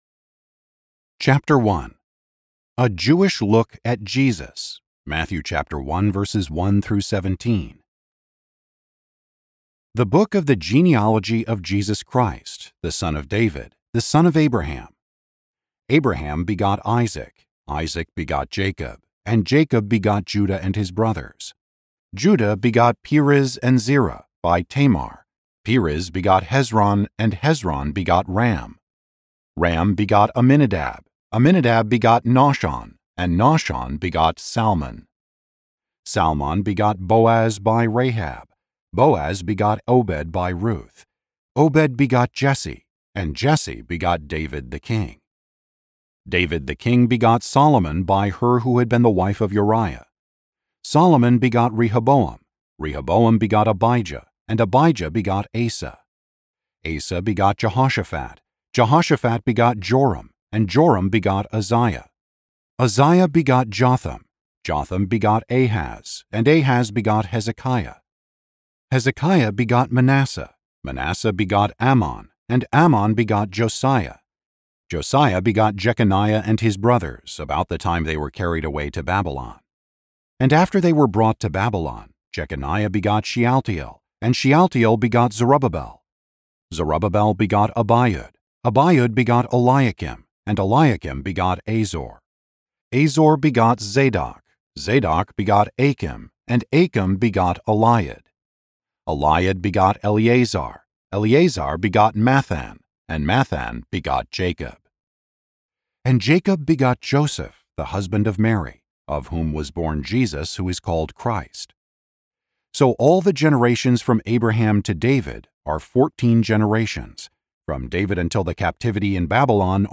Matthew: An Expositional Commentary: R.C. Sproul - Audiobook Download, Book | Ligonier Ministries Store